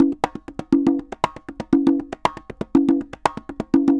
Congas_Salsa 120_1.wav